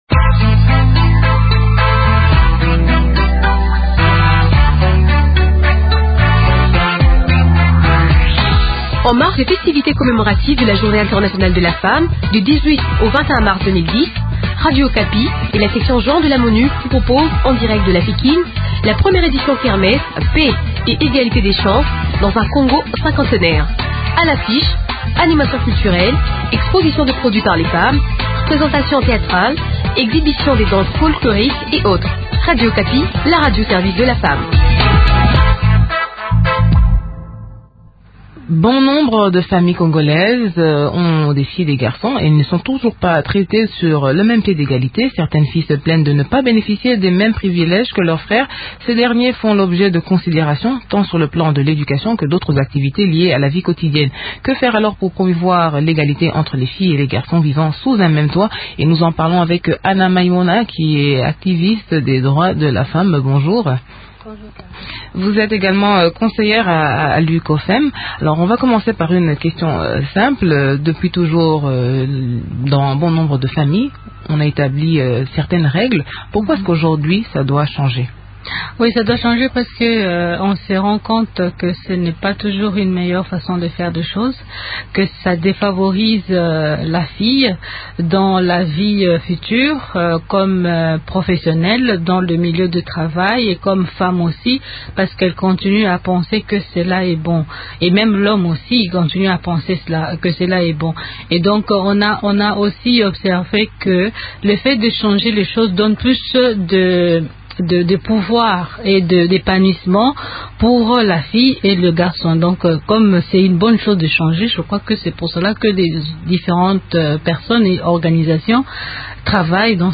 Que faire alors pour promouvoir l’égalité entre les filles et les garçons vivant sous un même toit ? Des éléments de réponse dans cette interview